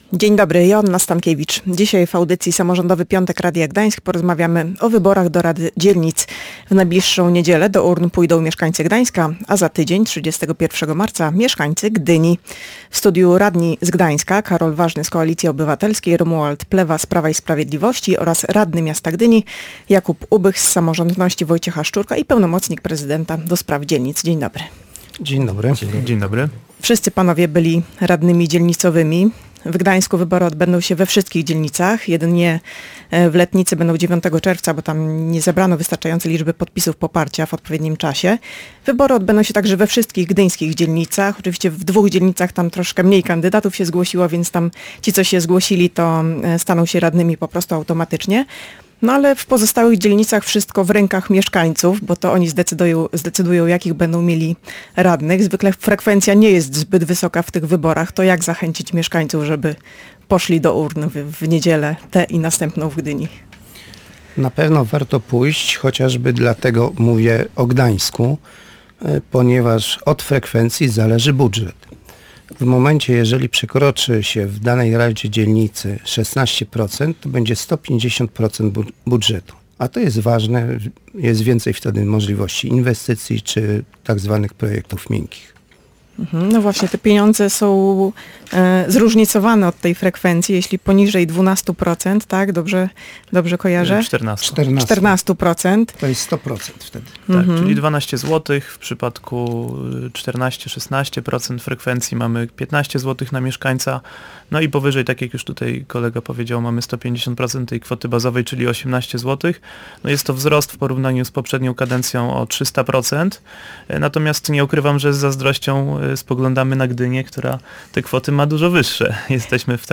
Niestety, frekwencja w wyborach do rad dzielnic jest zazwyczaj niska. Goście Radia Gdańsk podpowiadali, co mogłoby zachęcić mieszkańców do wzięcia udziału w głosowaniu.